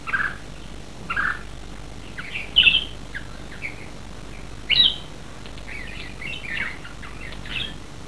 Here are a few samples of birds songs I recorded in the hotel's gardens.
Bulbuls
Bulbul3  (180 Ko) worth it!
bulbul3.wav